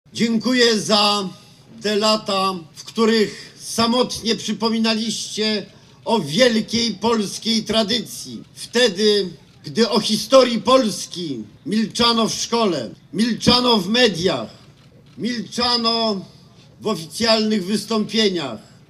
Przed inscenizacją bitwy na wzgórzu pomnikowym odbył się uroczysty apel, w którym wziął udział Minister Obrony Narodowej Antoni Macierewicz. Szef MON podziękował organizatorom inscenizacji i podkreślał wyjątkową rangę tych uroczystości.